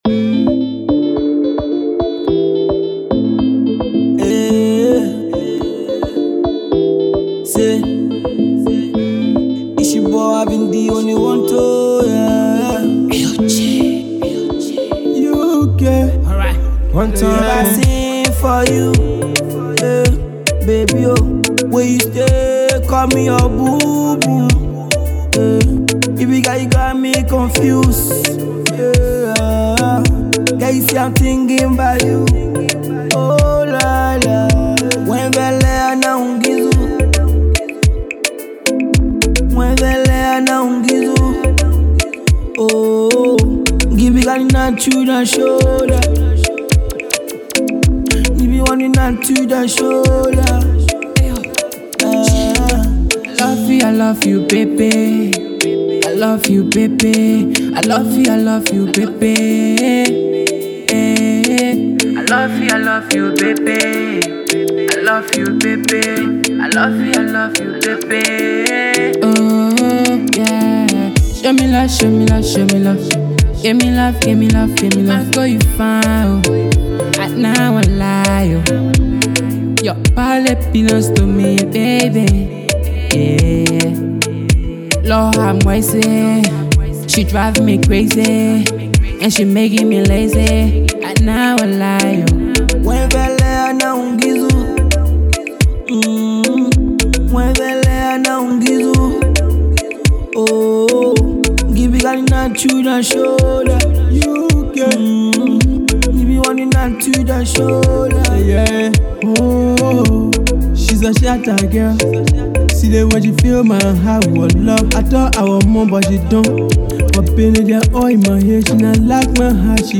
Some remake beat and little flavor added too.